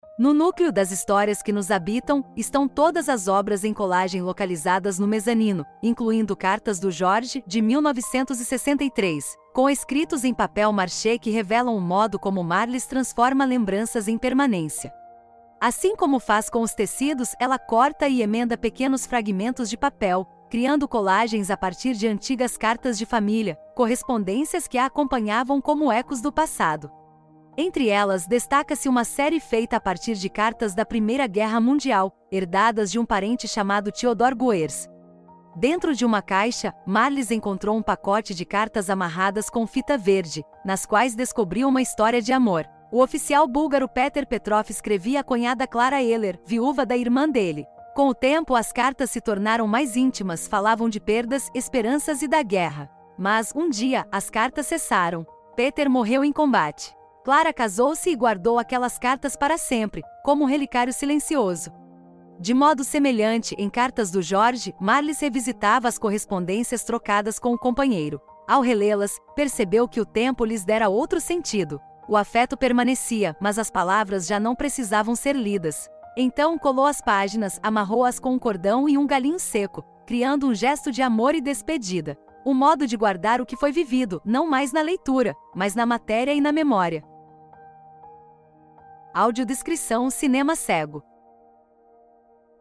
Audiodescrição do Núcleo 5 | Das Histórias que nos Habitam